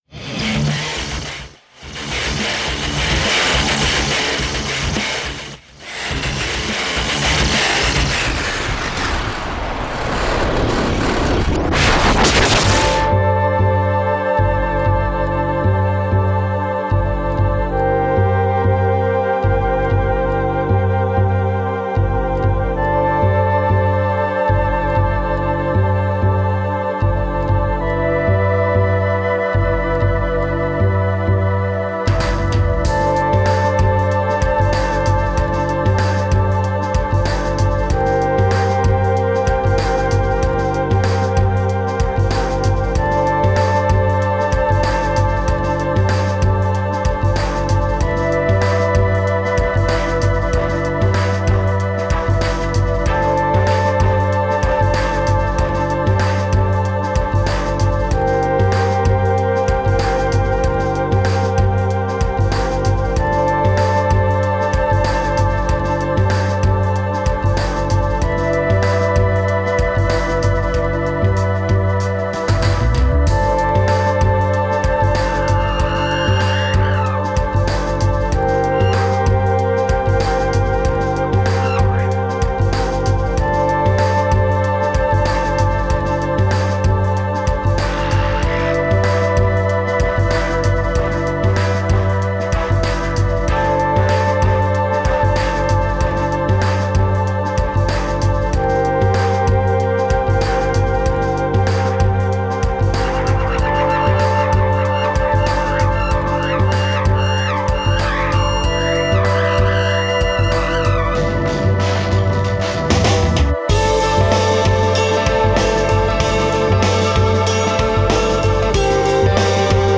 ゆるく展開して終わる曲です。 音量小さめで作業用にでもどうでしょう。